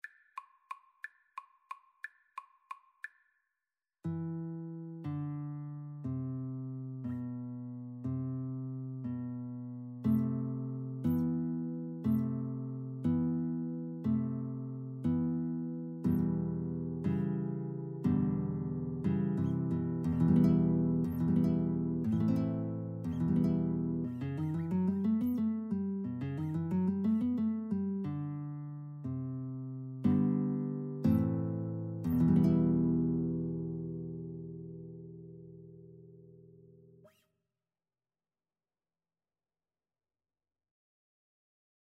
Free Sheet music for Guitar Duet
E minor (Sounding Pitch) (View more E minor Music for Guitar Duet )
3/4 (View more 3/4 Music)